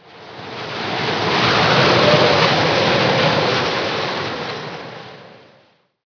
wind1.wav